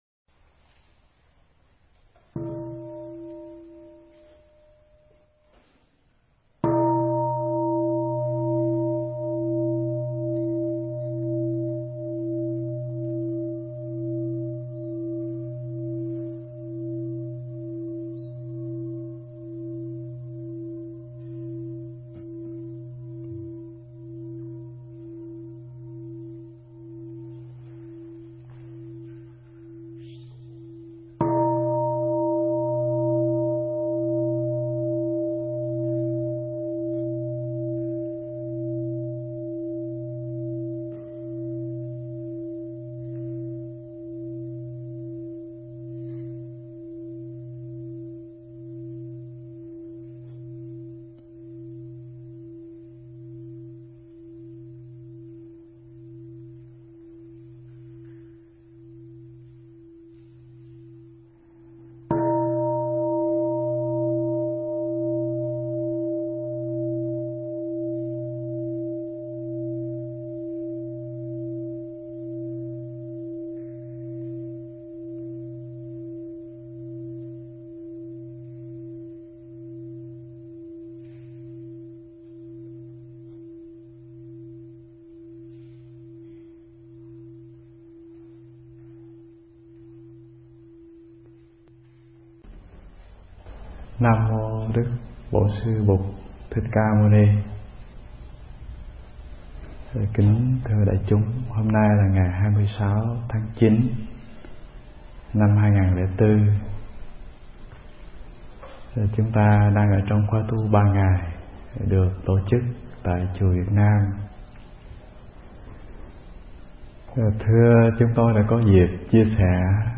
Nghe Mp3 thuyết pháp Chế Tác Hạnh Phúc
Tải mp3 pháp thoại Chế Tác Hạnh Phúc